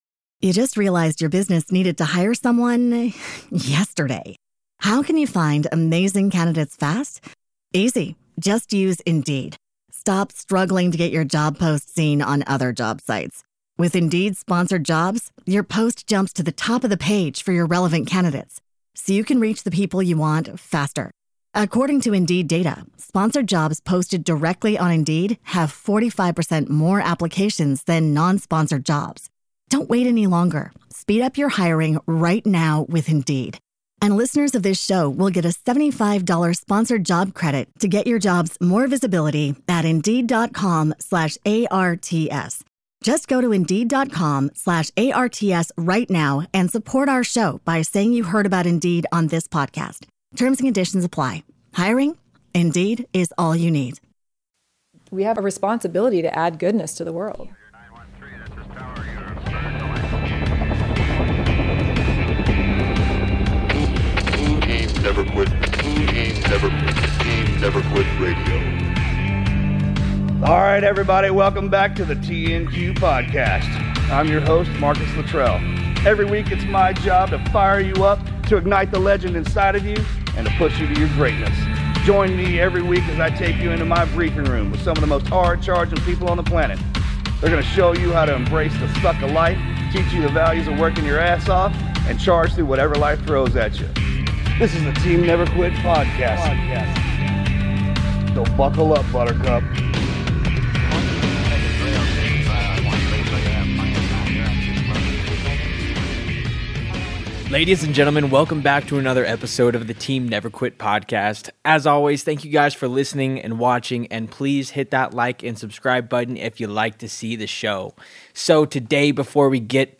Rising Above: A Conversation with Taya Kyle